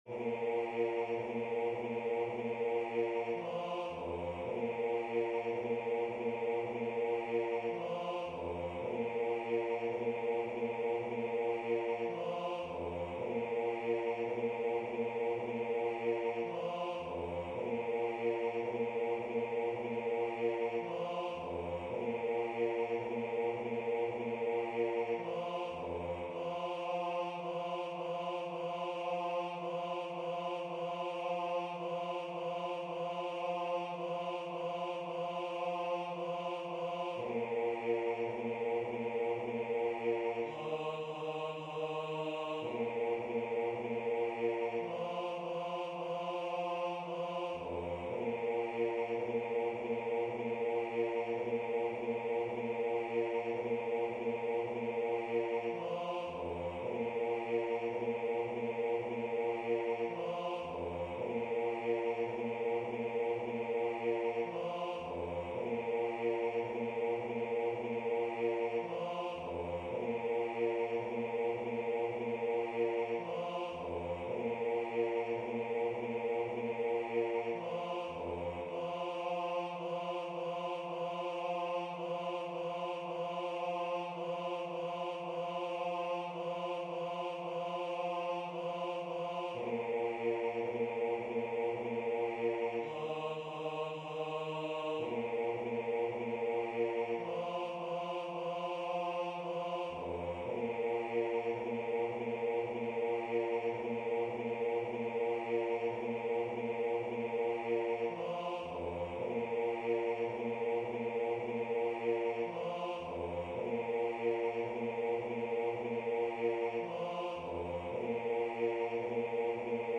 à 3 voix mixtes
MP3 rendu voix synth.
Hommes